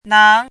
chinese-voice - 汉字语音库
nang2.mp3